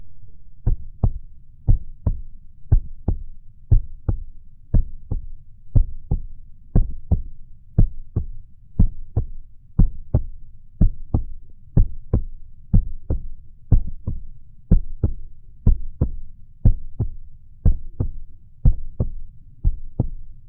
Clean Short Heartbeat Sound Effect Free Download
Clean Short Heartbeat